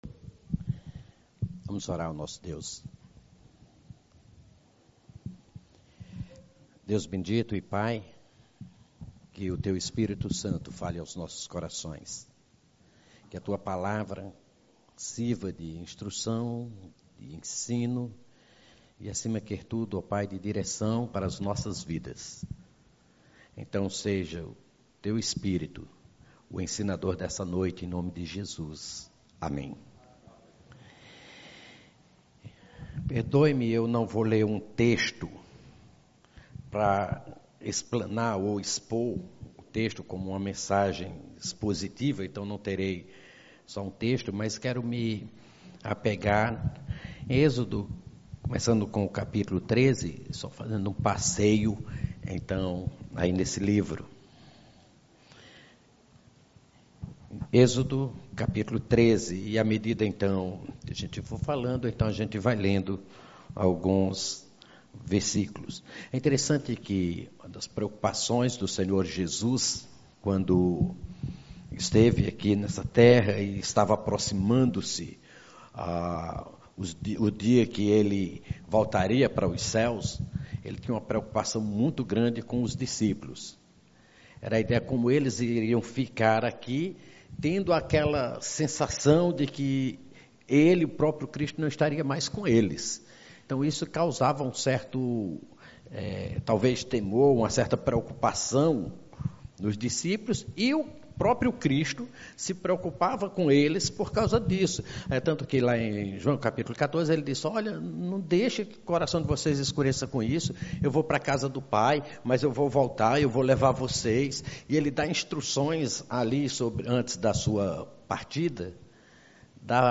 Igreja Batista Luz do Mundo, Fortaleza/CE.
Pregação por Convidado